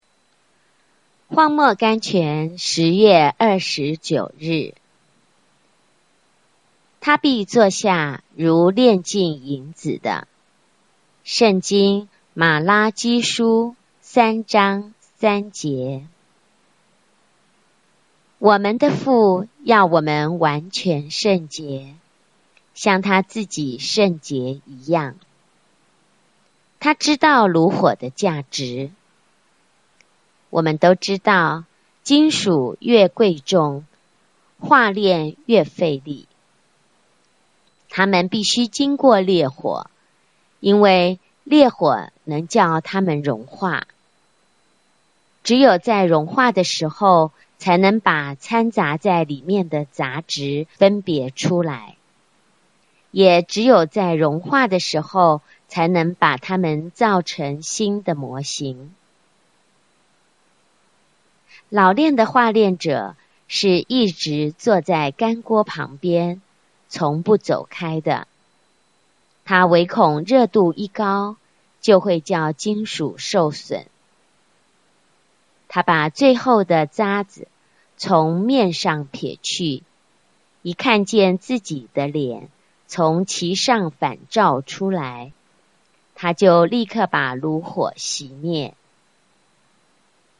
荒漠甘泉∕10 月 29 日 聆聽朗讀 原作∕Mrs. Charles E. Cowman 「祂必坐下如煉淨銀子的」（聖經瑪拉基書三章 3 節） 我們的父要我們完全聖潔，像祂自己聖潔一樣。